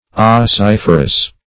Ossiferous \Os*sif"er*ous\, a.
ossiferous.mp3